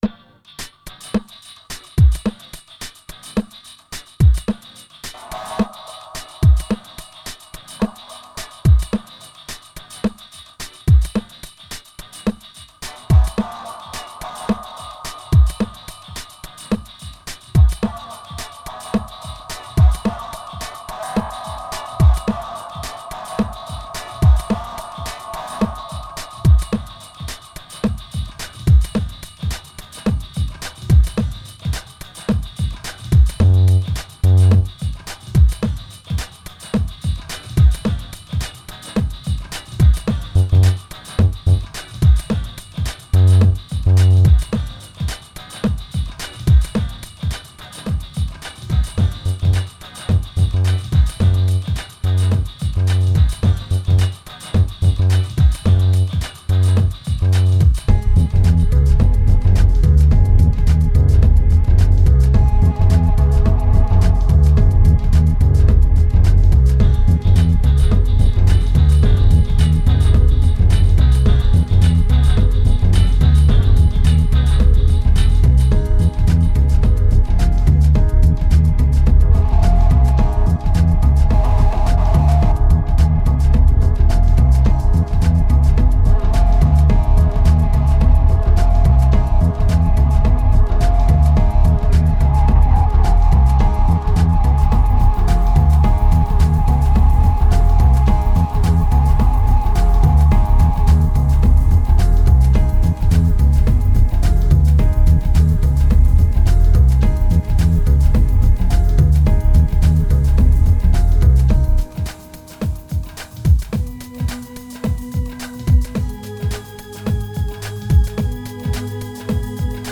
ambient album
2114📈 - 60%🤔 - 108BPM🔊 - 2010-11-12📅 - 142🌟